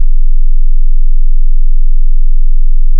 sixtyHertz=np.sin(2*np.pi * 60 *np.arange(300) /300)
less7sixtyhertz1.wav